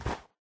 snow2.ogg